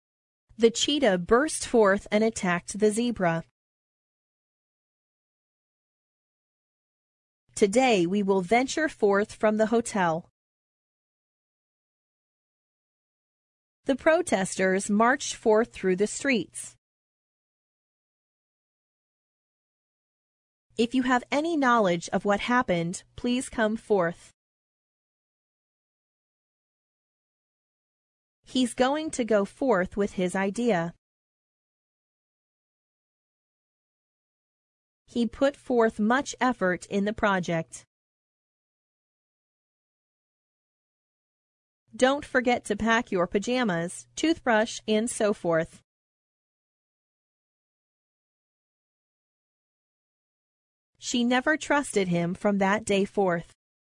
forth-pause.mp3